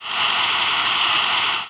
Common Yellowthroat diurnal flight calls